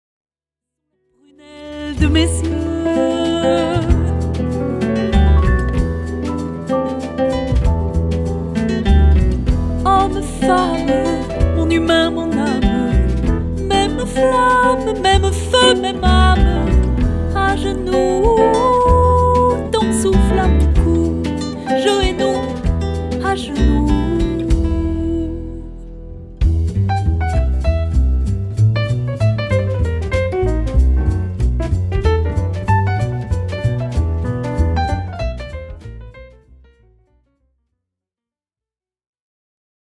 Du jazz au folk et au fado, en passant par le flamenco